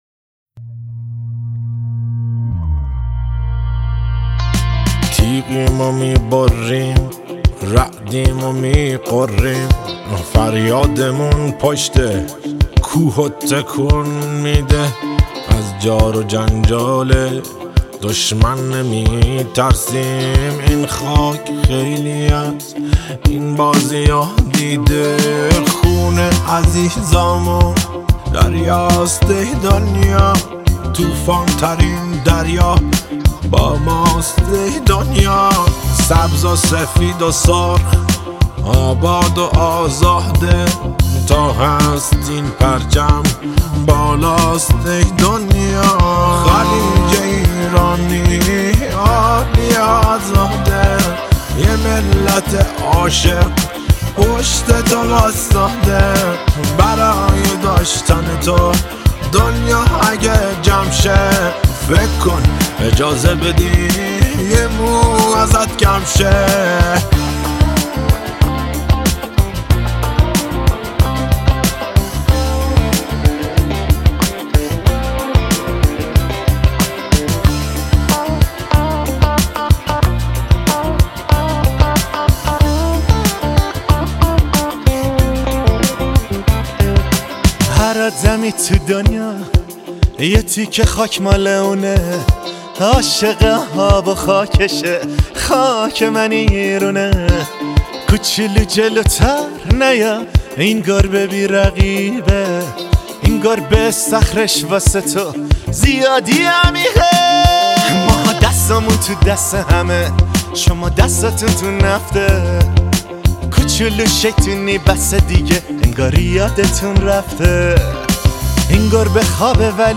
با حال و هوای وطن پرستانه و حماسی